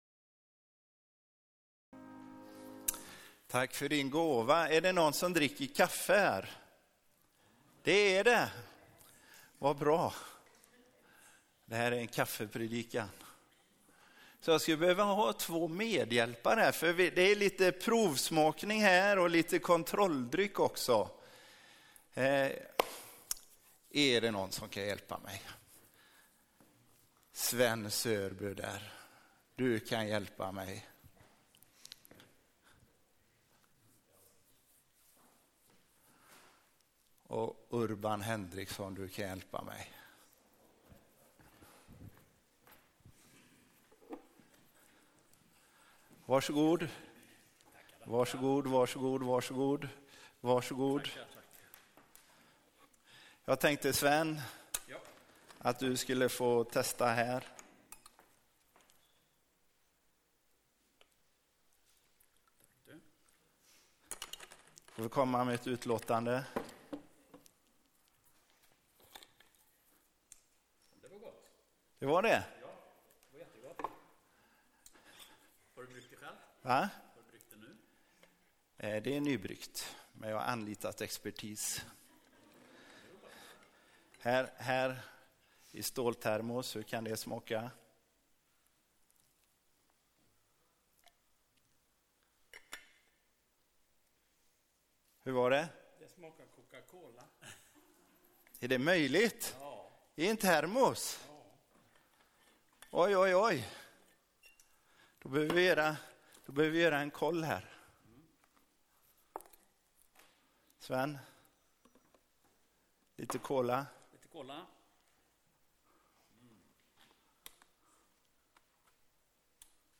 predikar i gudstjänsten 11 aug